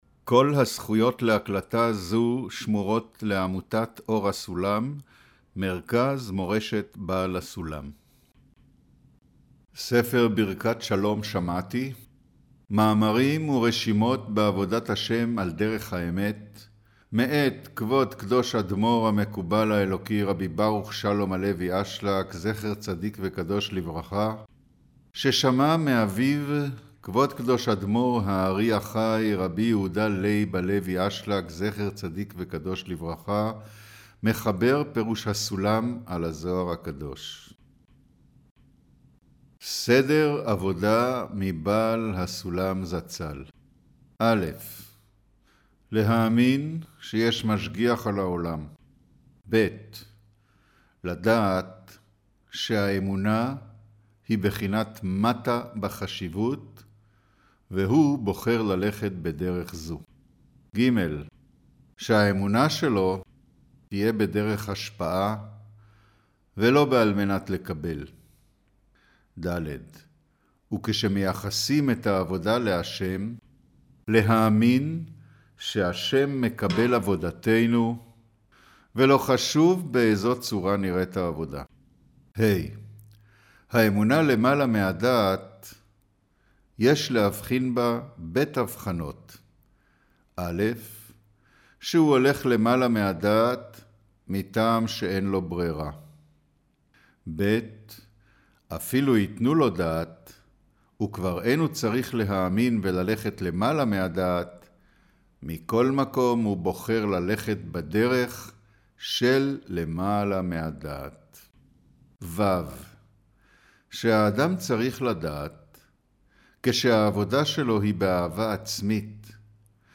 אודיו - קריינות מאמר